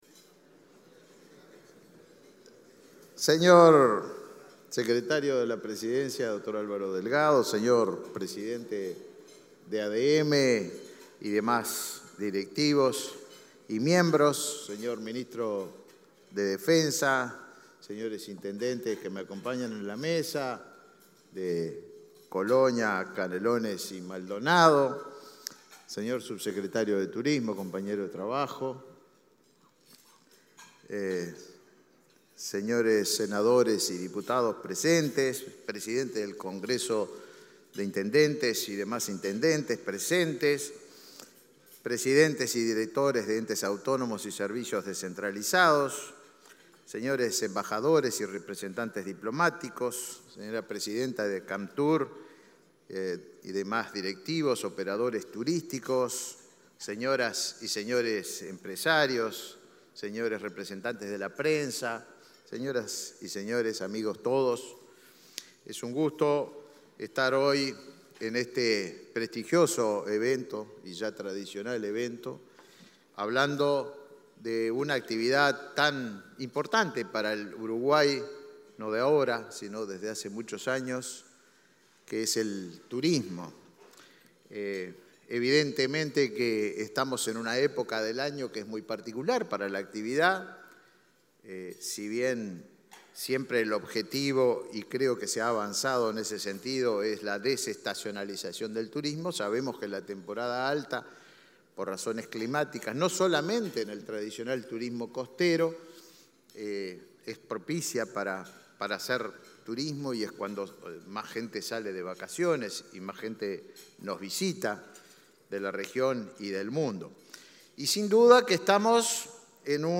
Palabras del ministro de Turismo, Tabaré Viera
Palabras del ministro de Turismo, Tabaré Viera 08/11/2022 Compartir Facebook X Copiar enlace WhatsApp LinkedIn Autoridades del Gobierno participaron en un almuerzo de trabajo denominado Uruguay de Cara a la Temporada 2023, este 8 de noviembre. El ministro de Turismo, Tabaré Viera, reflexionó sobre el aumento de propuestas y la recuperación económica del sector.